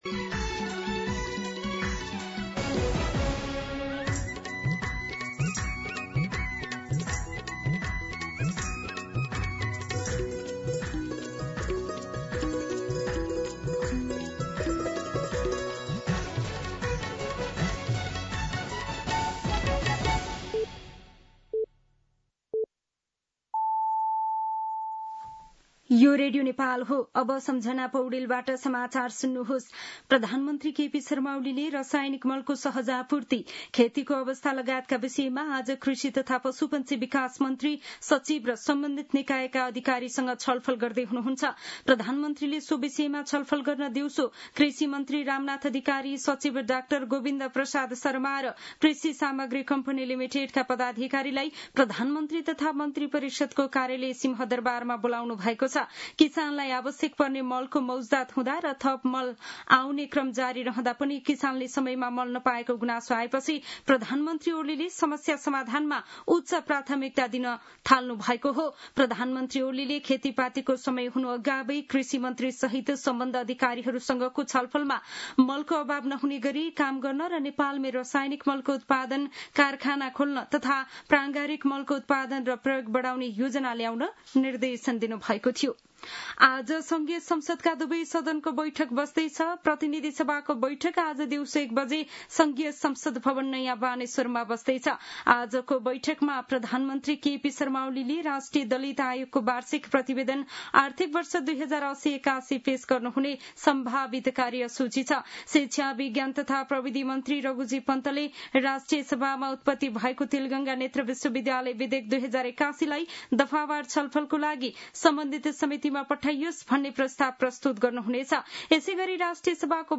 मध्यान्ह १२ बजेको नेपाली समाचार : २७ साउन , २०८२